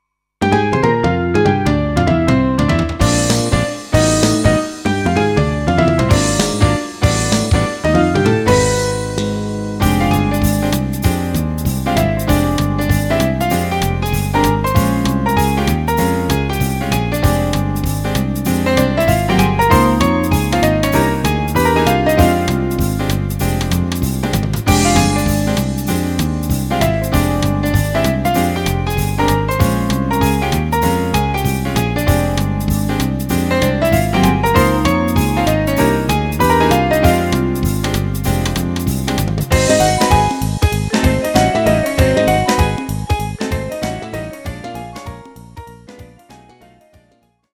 Horn in F or Eb and Piano
Performance